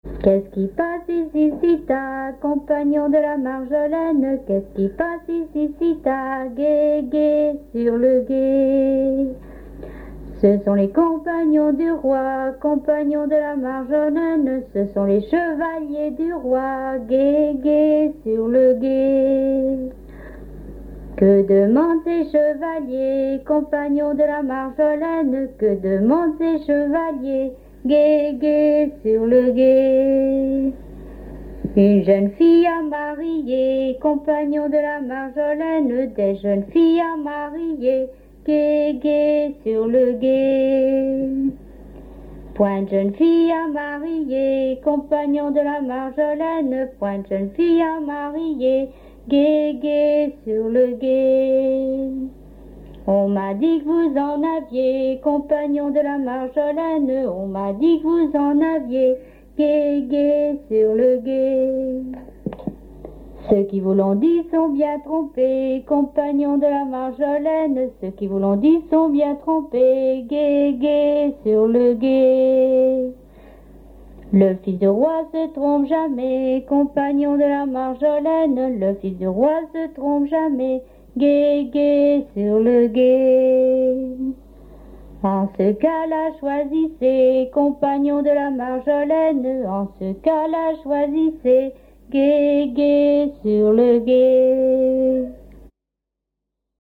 Chevalier du guet - 007804 Thème : 0078 - L'enfance - Enfantines - rondes et jeux Résumé : Qu'est-ce qui passe ici si tard ?
danse : ronde
Pièce musicale inédite